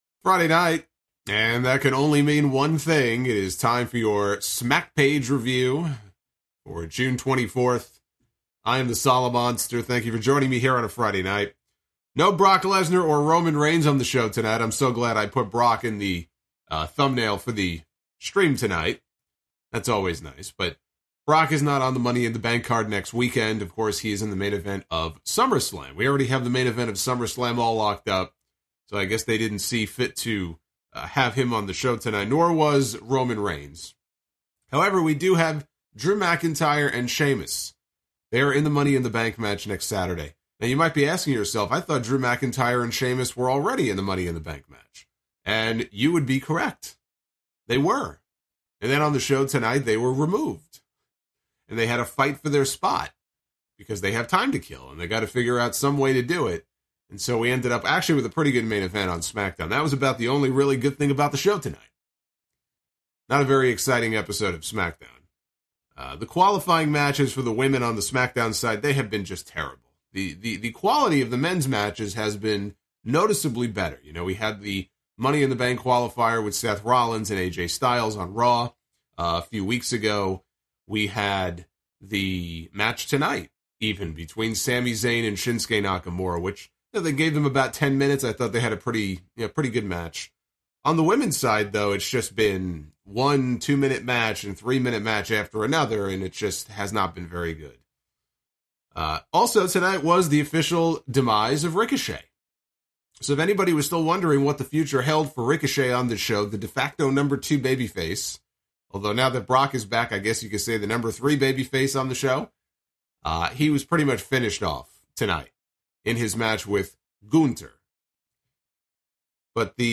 Reviewing WWE Smackdown and AEW Rampage for June 24th with an Intercontinental championship match, more Money in the Bank qualifiers and the final build before AEW Forbidden Door this Sunday. I'm also miserable with COVID, so apologies for the audio, but I did the best I could...